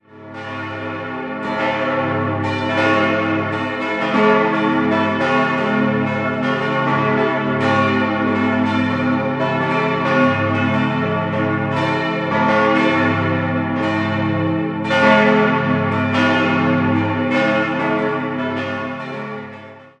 Die Reformierte Kirche ist ein bedeutender, von 1822 bis 1828 errichteter spätklassizistischer Bau und als Querkirche angelegt. 4-stimmiges Geläut: a°-cis'-e'-a' Die Glocken wurden 1892 von der Gießerei Keller in Zürich-Unterstrass gegossen.